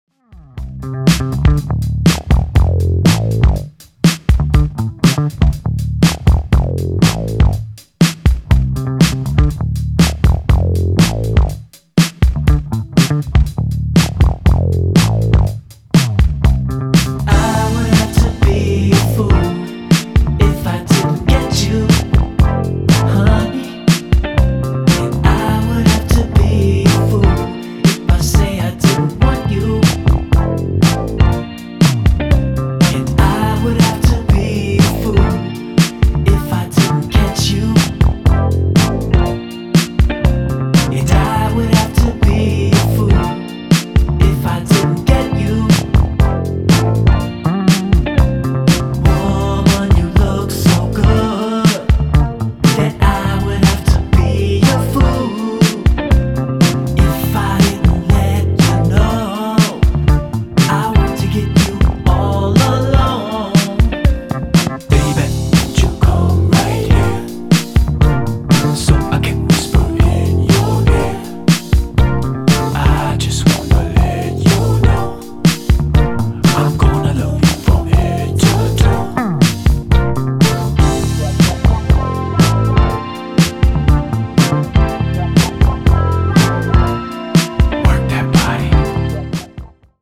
(Vocal)